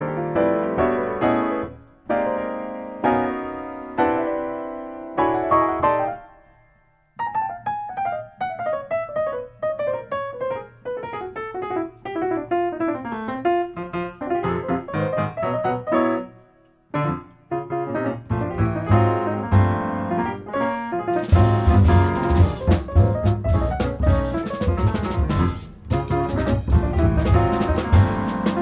Klassik Musik